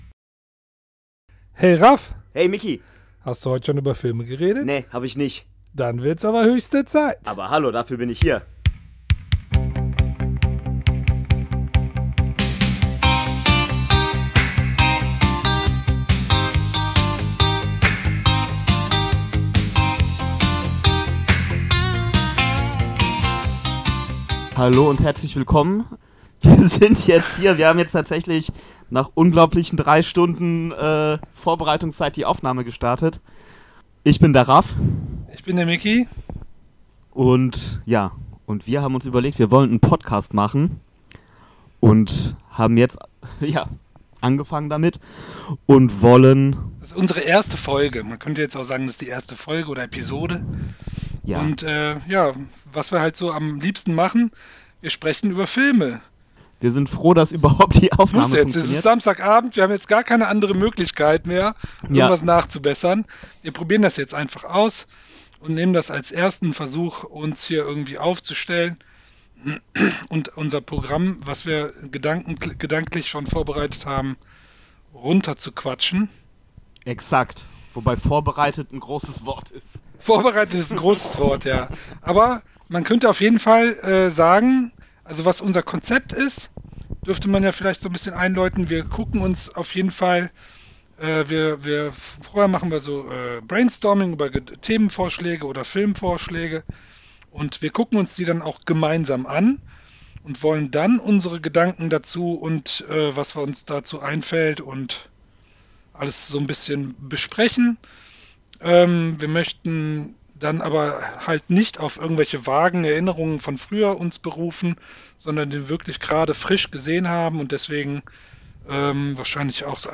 Bonus für Euch: unser erster Gehversuch! Professionelles Equipment? Nope!